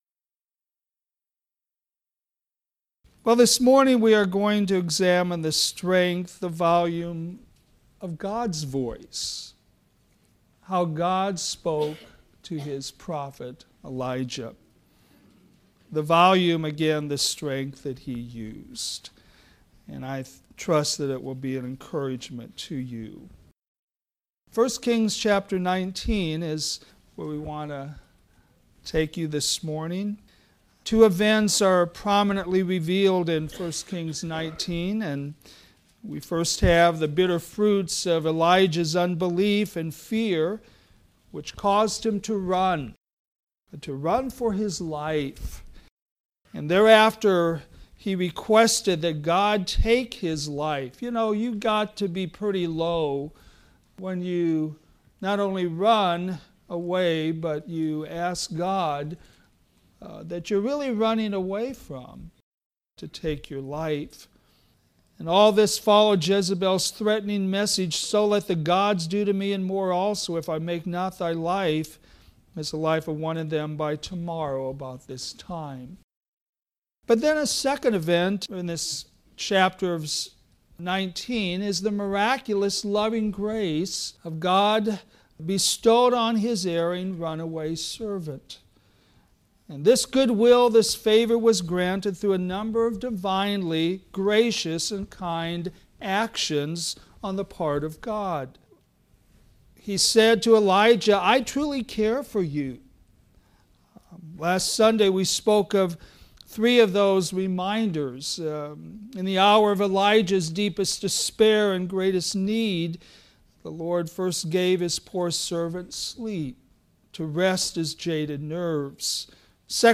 All sermons available in mp3 format
Sunday AM